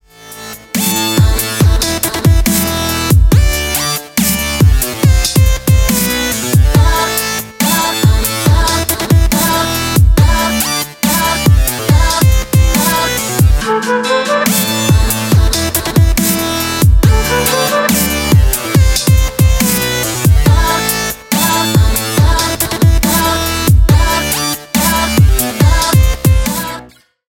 Ремикс # Электроника